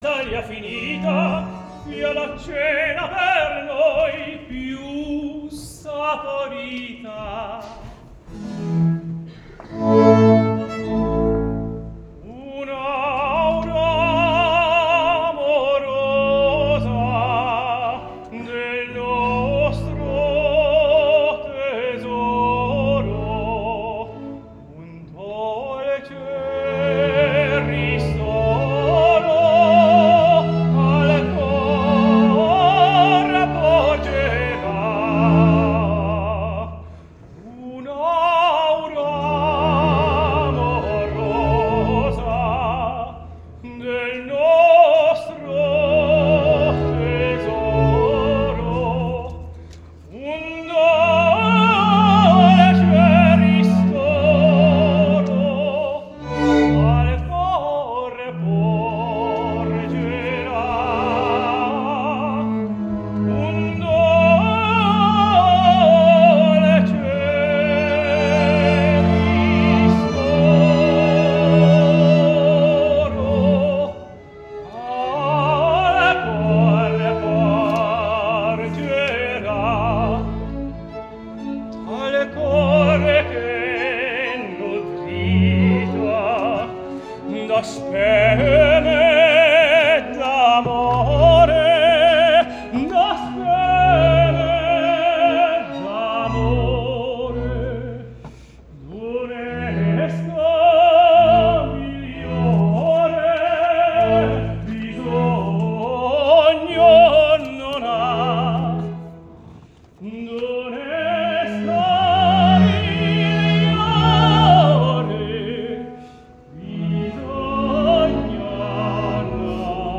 Tenor
Festival Pistoia